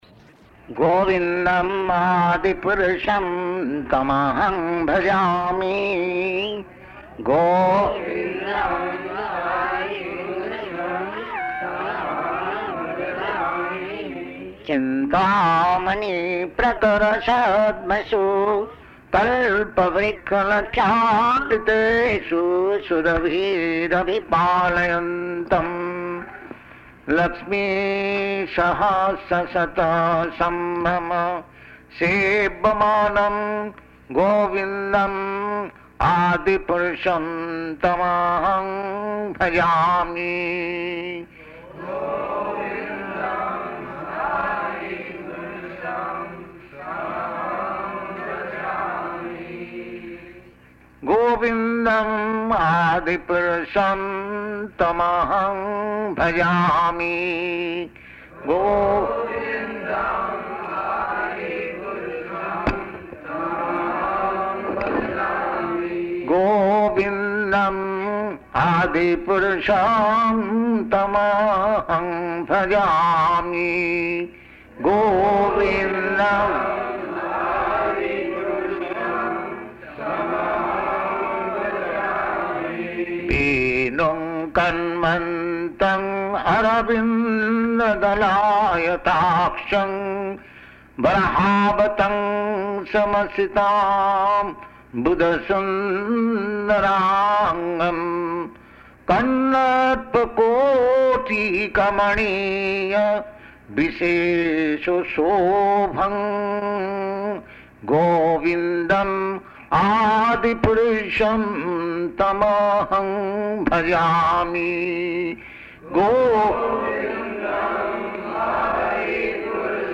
Location: Montreal
[Poor audio]
[chants Govindam prayers, with devotees responding]